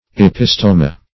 Search Result for " epistoma" : The Collaborative International Dictionary of English v.0.48: Epistoma \E*pis"to*ma\, Epistome \Ep"i*stome\, n. [NL. epistoma, fr. Gr.